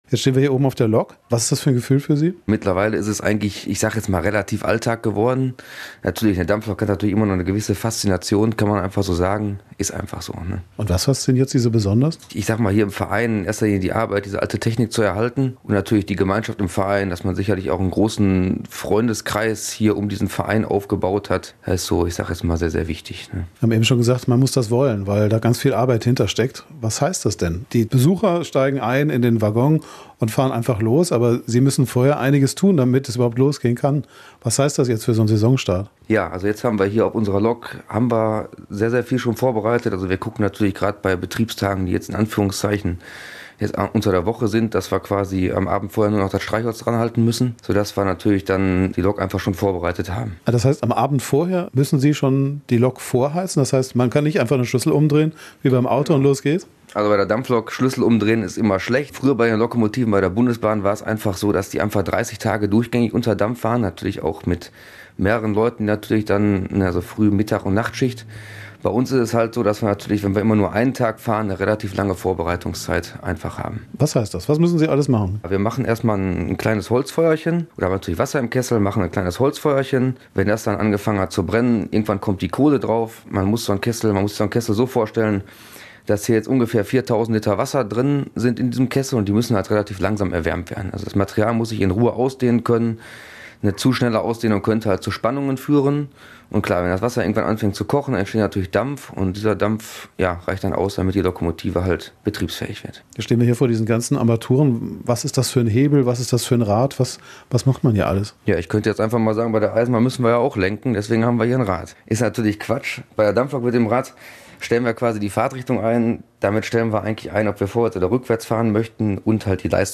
Die Hespertalbahn startet am 1. Mai in die neue Saison. Wir waren bei den Vorbereitungen in Kupferdreh und haben uns ein Stück Industriegeschichte angesehen.